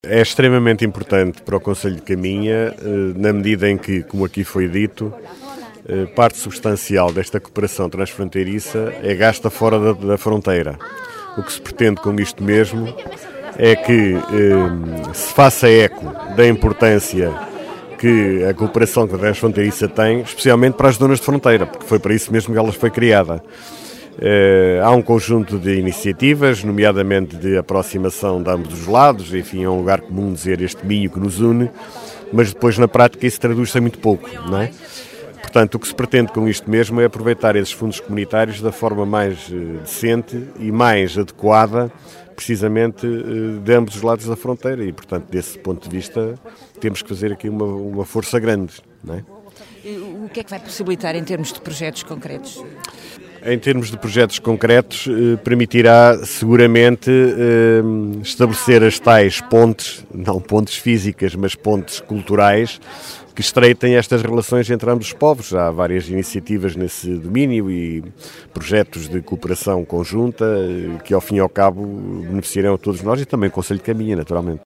Guilherme Lagido, vice-presidente da Câmara de Caminha destaca a importância deste pacto.